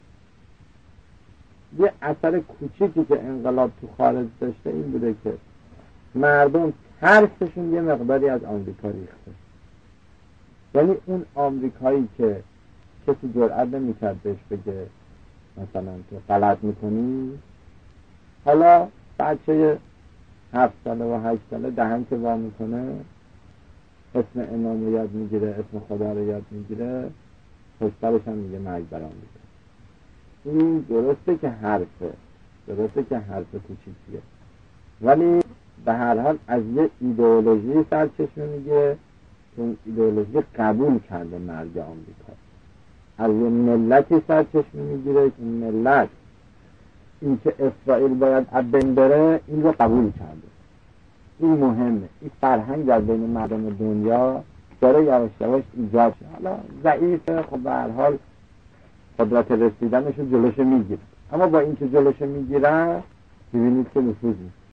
در این صوت صدای شهید حسن باقری را می شنوید که می گوید:یه اثر کوچیکی که انقلاب تو خارج داشته این بوده که مردم ترس‌شون یه مقداری از آمریکا ریخته.